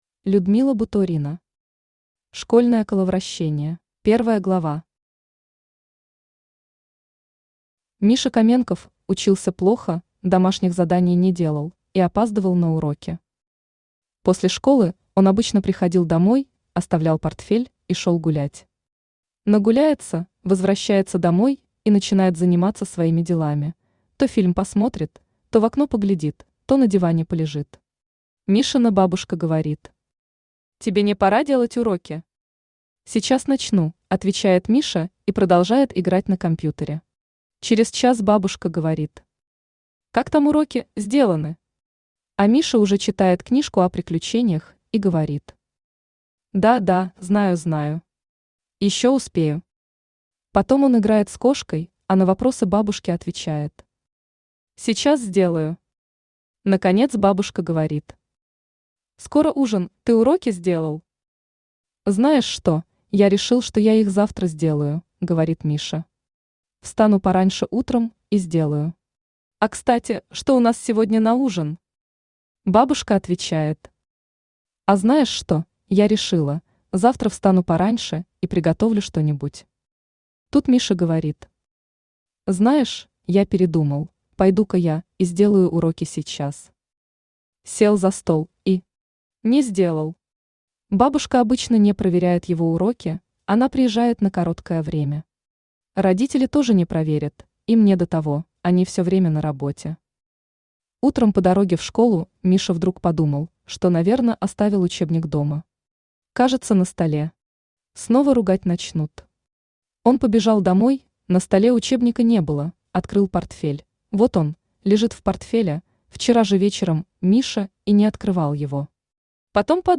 Аудиокнига Школьное коловращение | Библиотека аудиокниг
Aудиокнига Школьное коловращение Автор Людмила Буторина Читает аудиокнигу Авточтец ЛитРес.